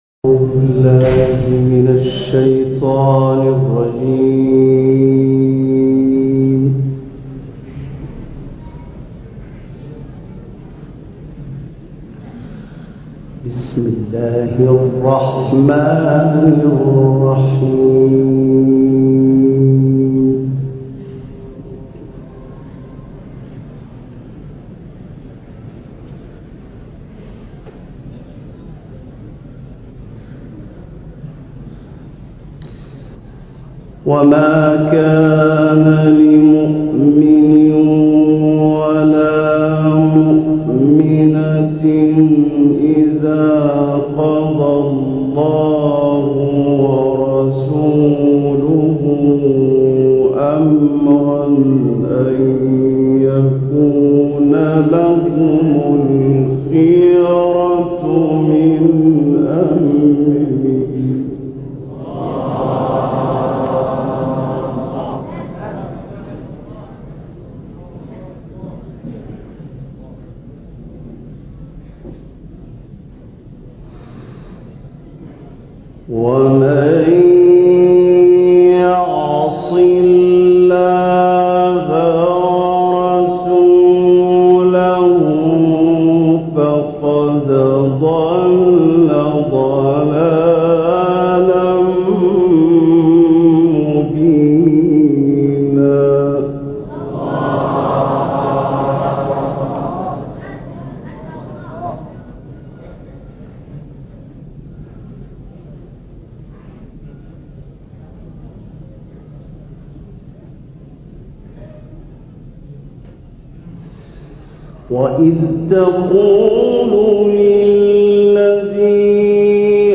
هم اکنون تلاوت انجام شده در آن شب خاطره‌انگیز را با صدای این استاد مرحوم تقدیمتان میکنم. سور مبارکه احزاب و مطففین. مع الاسف این تلاوت در دقایق پایانی ناقص ضبط شده.